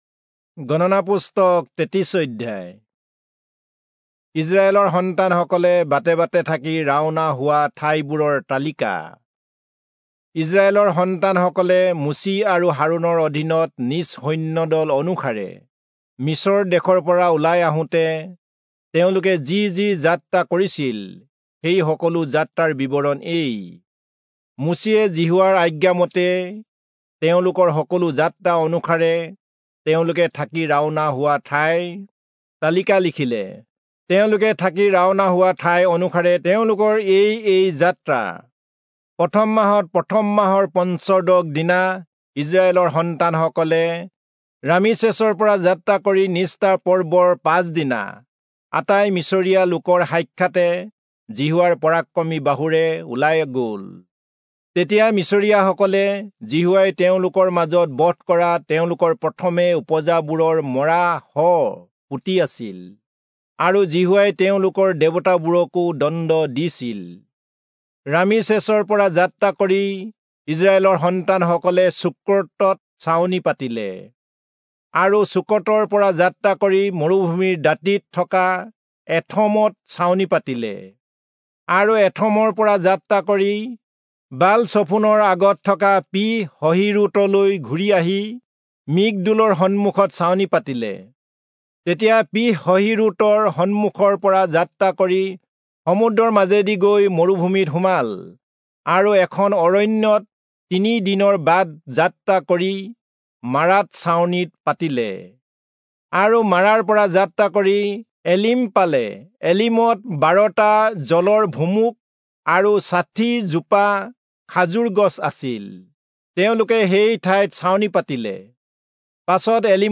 Assamese Audio Bible - Numbers 20 in Mov bible version